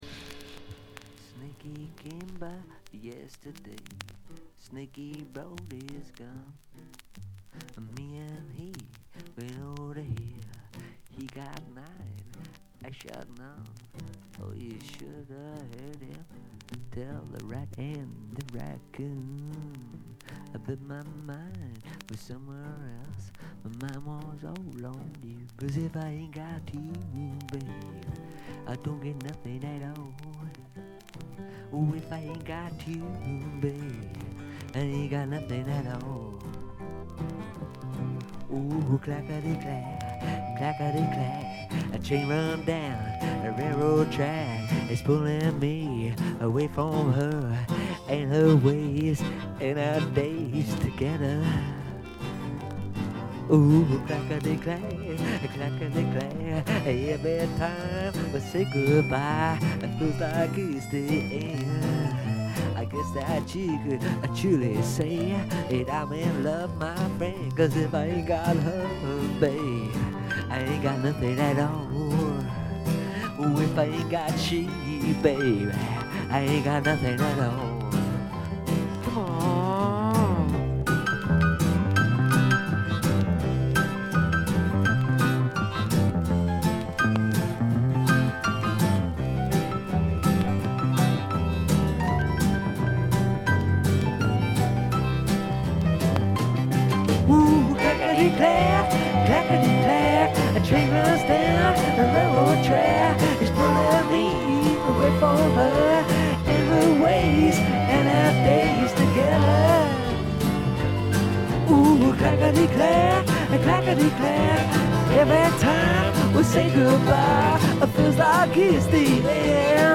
バックグラウンドノイズ、細かなチリプチ多め大きめ。
特に目立つノイズはありません。
試聴曲は現品からの取り込み音源です。